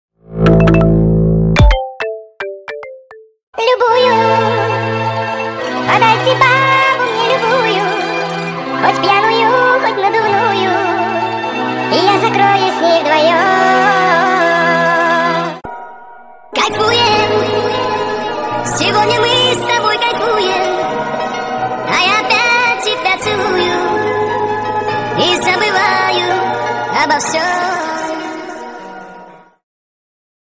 • Качество: 192, Stereo
мелодичные
цикличные